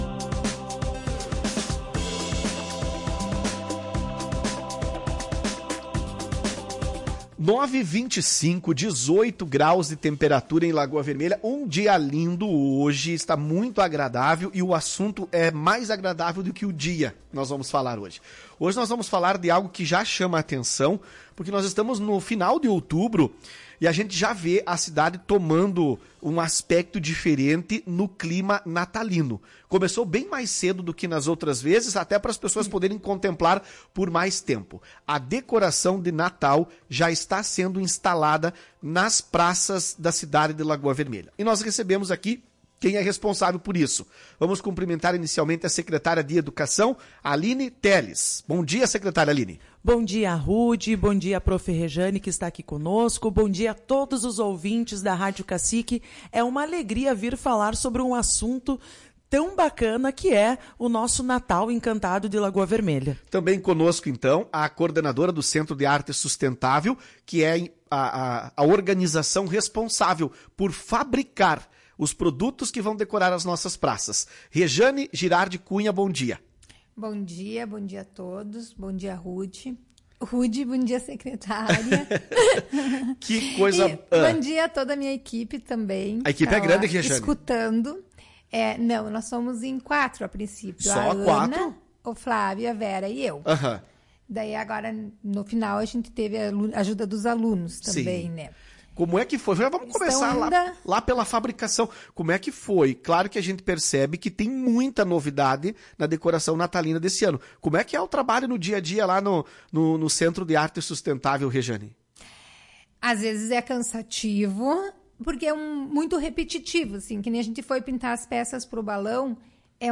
Ouça entrevista com secretária da Educação